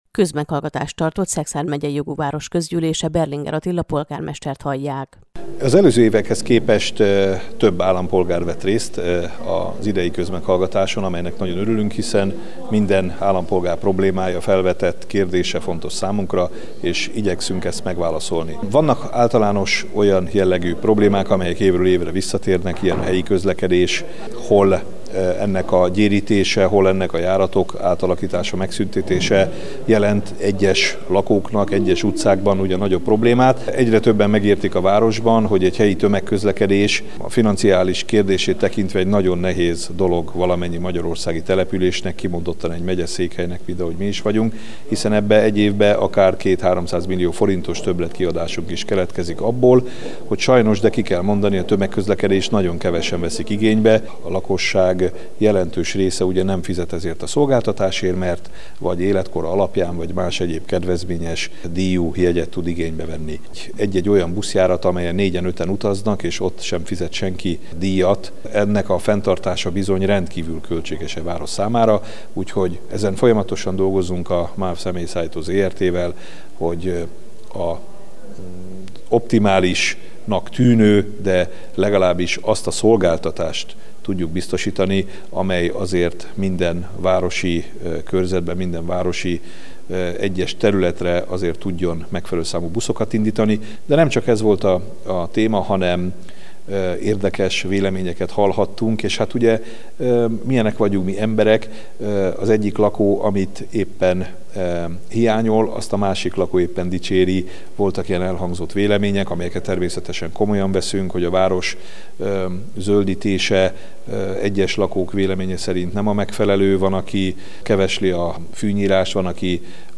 kozmegallgatas_szekszardon_web_exp.mp3